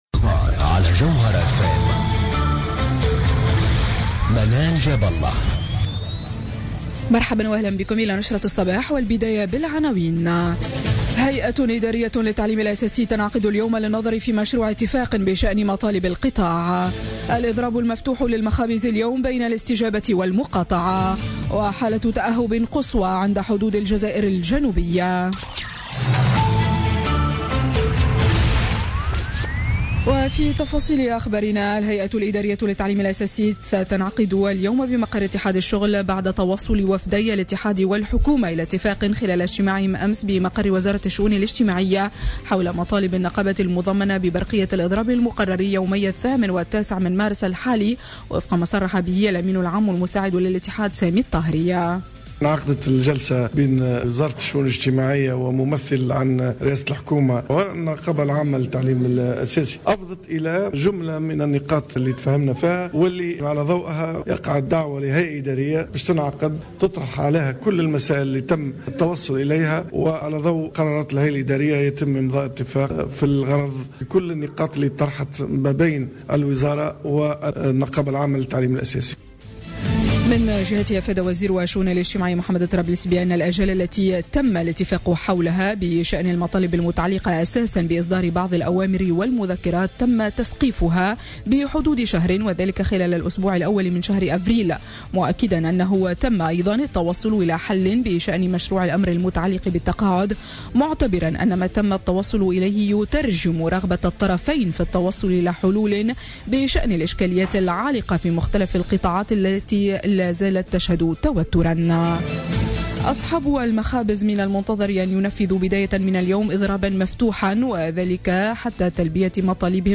نشرة أخبار السابعة صباحا ليوم الإثنين 6 مارس 2017